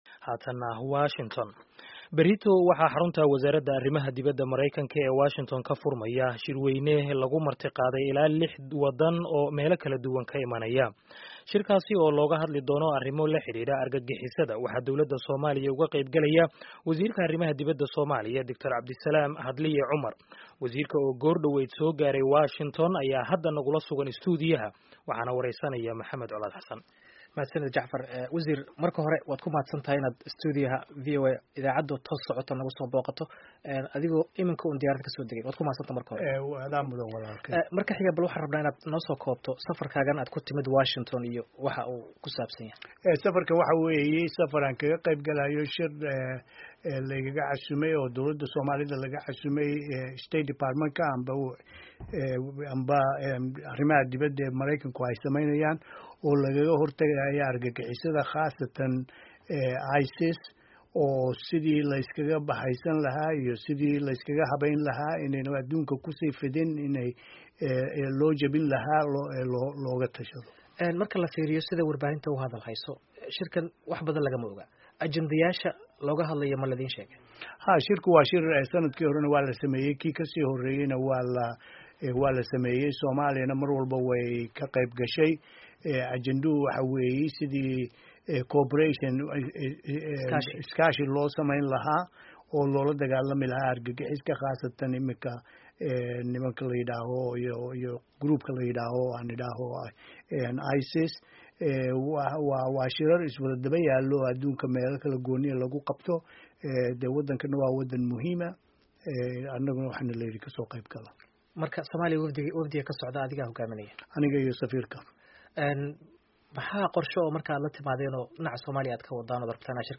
Wareysi: Hadliye